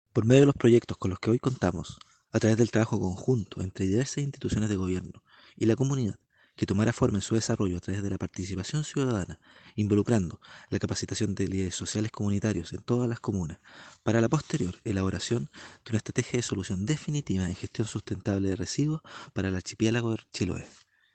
Al respecto, el Seremi del Medio Ambiente de la Región de Los Lagos, Klaus Kosiel, destacó el rol de las organizaciones sociales.